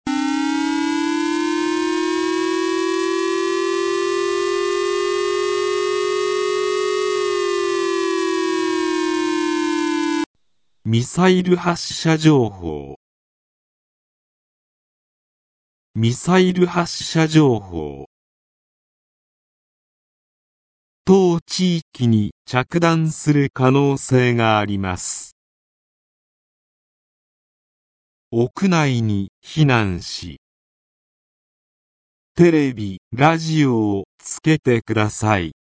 全国瞬時警報システム（J－ALERT）による放送例
武力攻撃等に関する情報の通報（例）